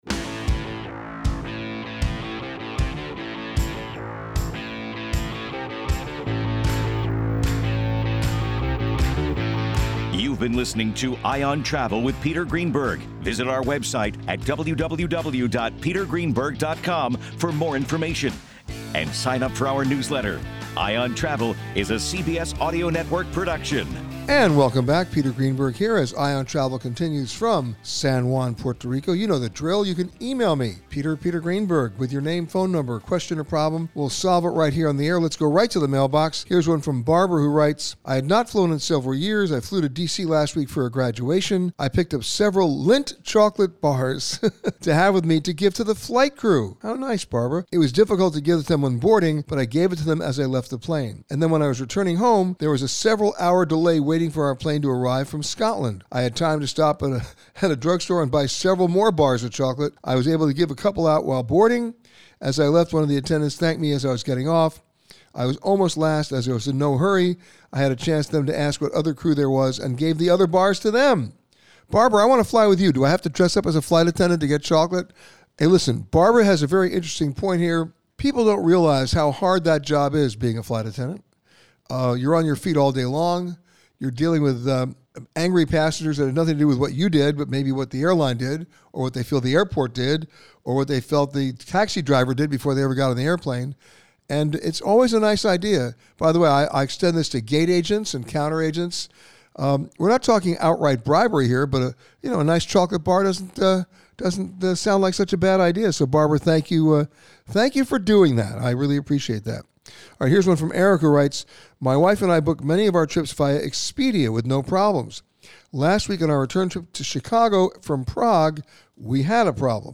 This week, Peter answered your questions from The American Society of Travel Advisors Conference in San Juan, Puerto Rico.